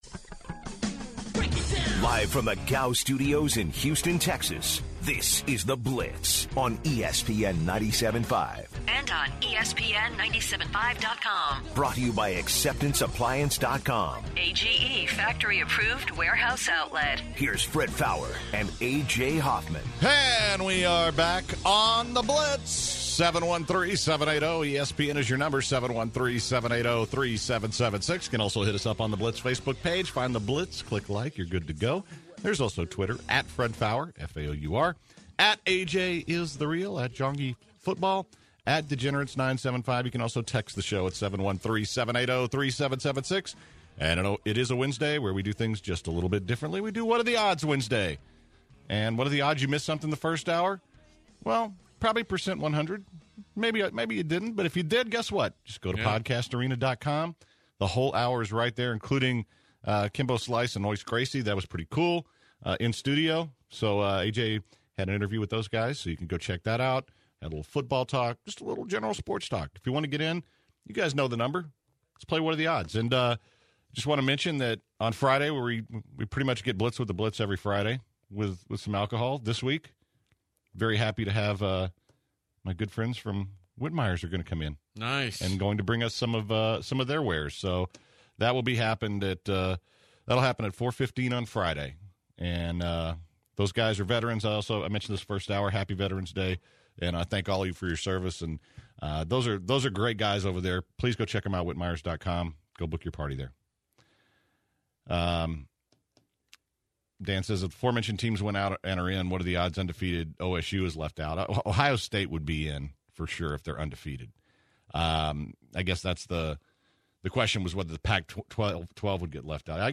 The hour started off with a lot of callers and specifically what are the odds questions. The guys talked about possibilities of Mathew Stafford being available next year and the College Football Final Four standings. The hour comes to a close with the Gem of The Day and Ronda Rousey's thoughts on Bernie Sanders.